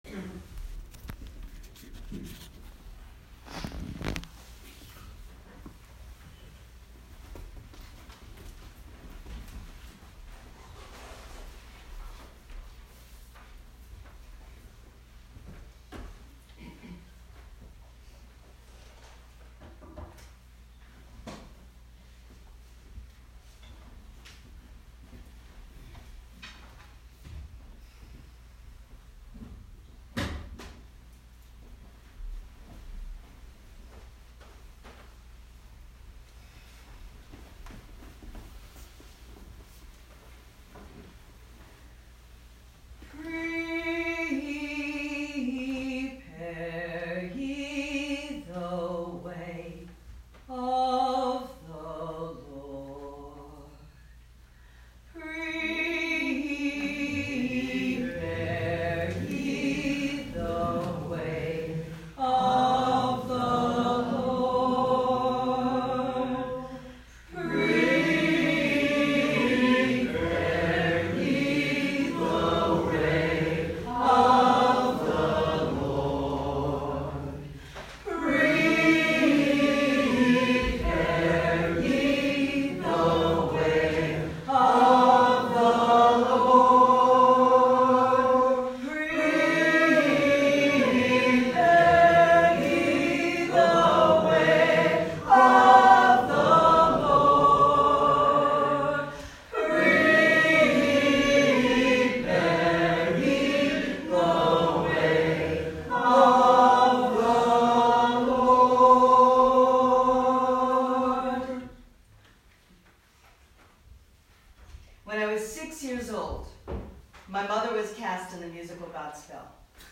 Prepare ye the way of the Lord… (sung 4x)
Sermon-Prepare-Ye-The-Way.m4a